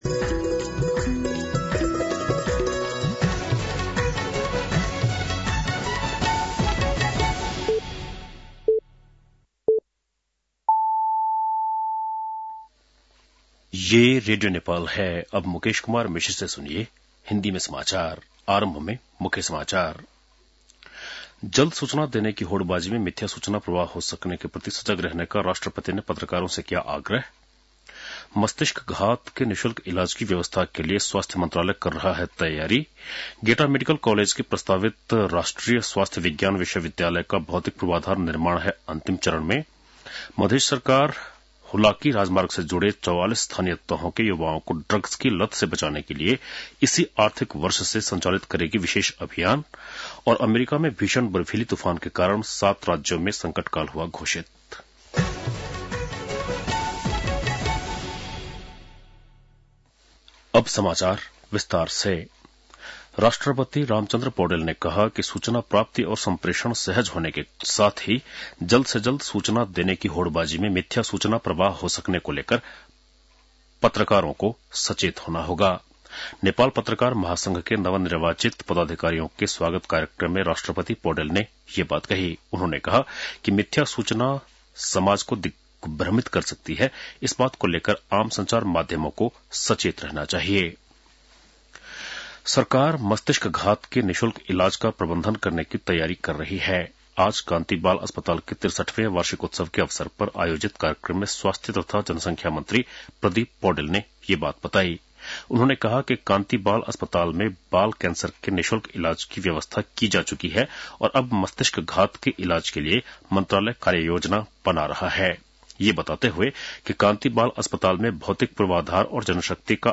बेलुकी १० बजेको हिन्दी समाचार : २३ पुष , २०८१